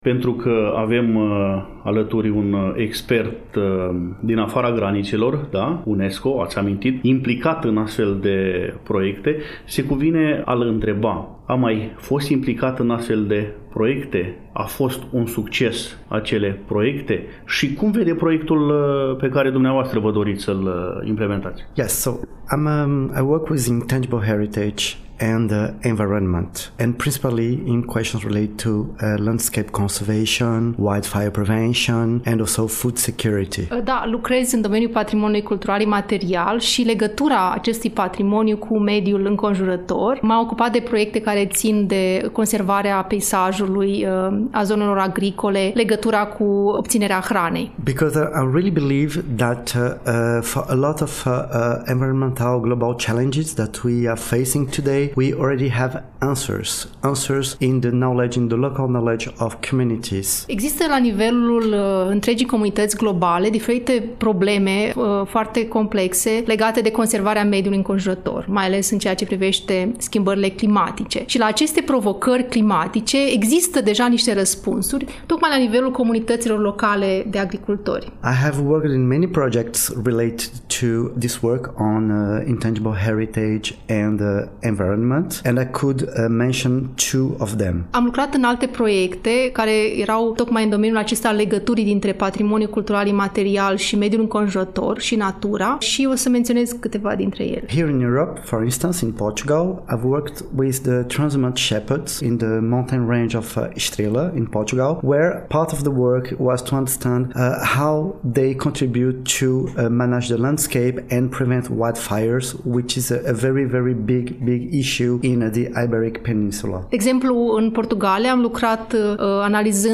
Pentru a afla amănunte concrete atât despre obiectivele proiectului, cât și despre principalele activități din cadrul acestuia, am invitat la dialog pe membrii echipei care se ocupă de implementare.